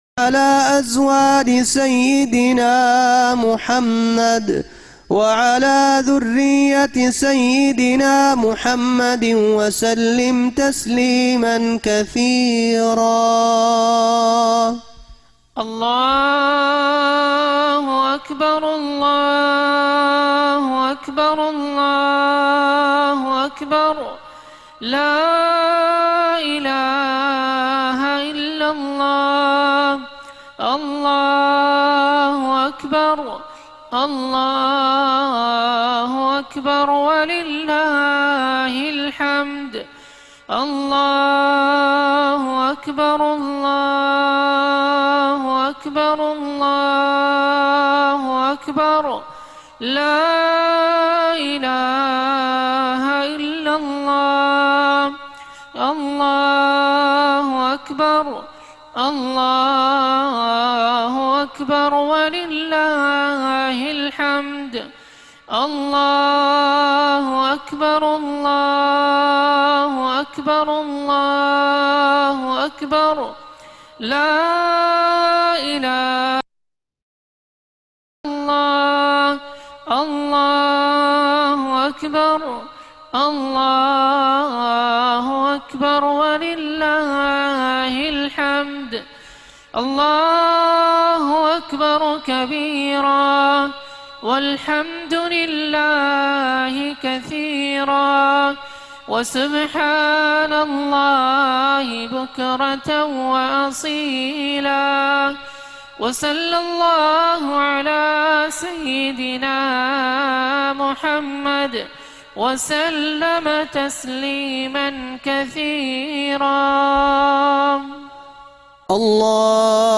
Eid-ul-Fitr Message